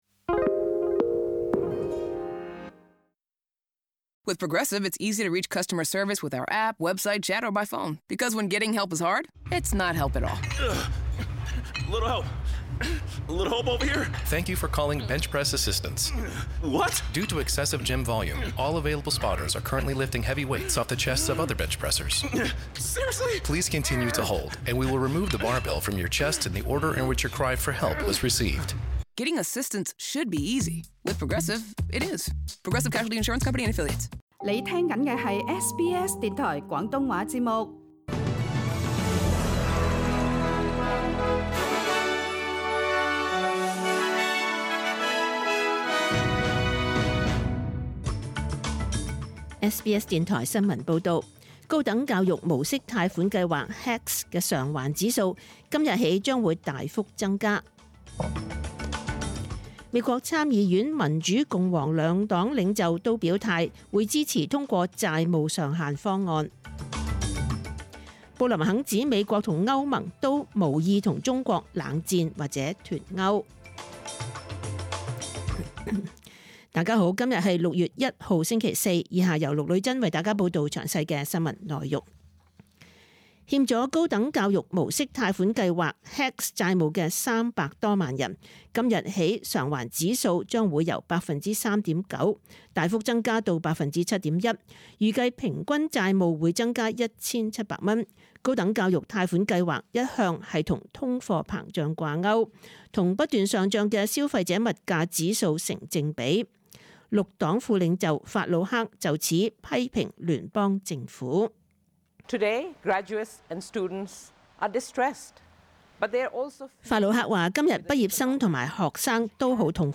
SBS 中文新聞 （6月1日） 12:06 SBS 廣東話節目中文新聞 SBS廣東話節目 View Podcast Series Follow and Subscribe Apple Podcasts YouTube Spotify Download (11.09MB) Download the SBS Audio app Available on iOS and Android 請收聽本台為大家準備的詳盡早晨新聞。 新聞提要 高等教育無息貸款計劃HECS的償還指數今日起大幅增加。 美國參議院民主共和兩黨領袖都表態支持通過債務上限方案。 布林肯指美國同歐盟都無意和中國冷戰或脫勾。